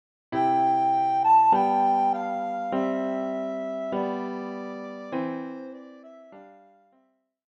deutsches Weihnachtslied